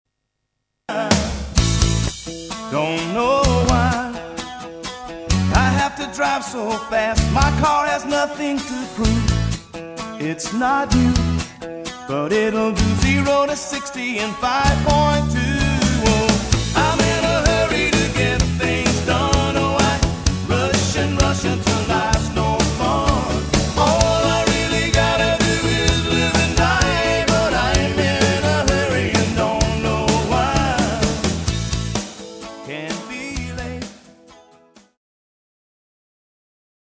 Country & Western Hits